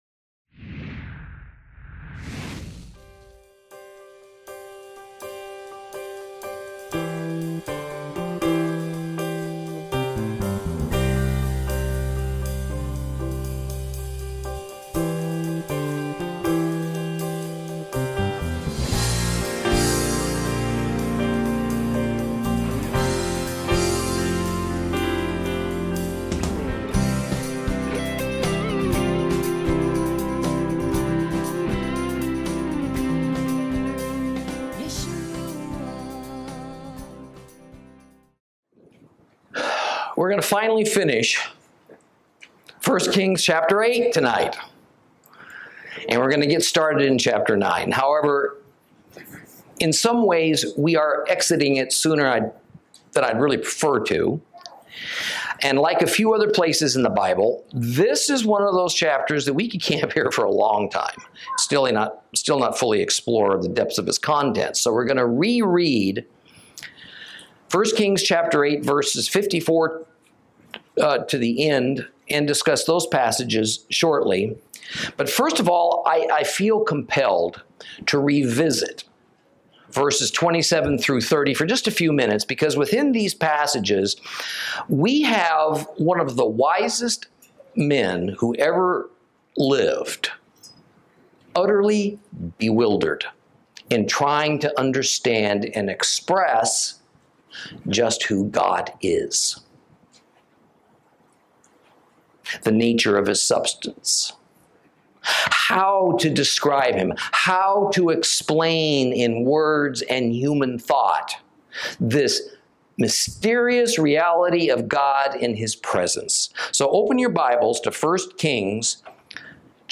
Lesson 16 Ch8 Ch9 - Torah Class